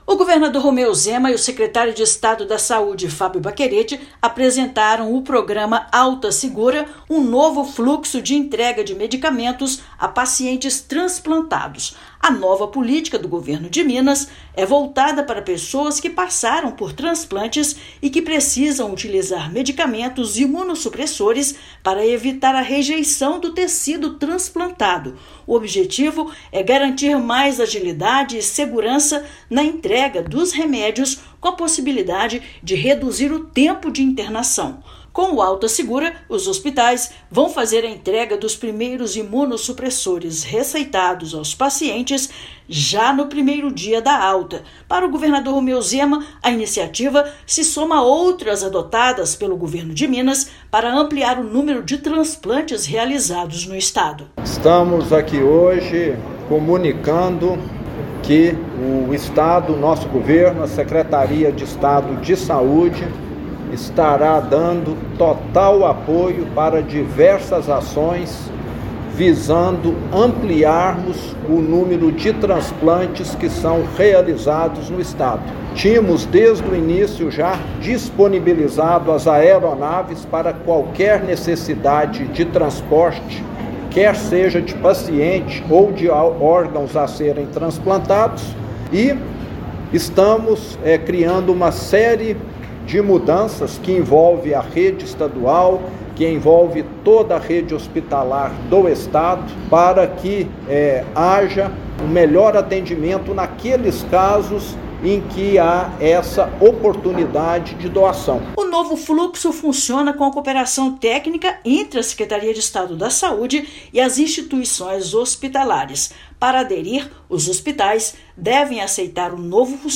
No mês em que são realizadas ações de sensibilização para a doação de órgãos (Setembro Verde), Estado anuncia novo fluxo para agilizar entrega de medicamentos a essas pessoas. Ouça matéria de rádio.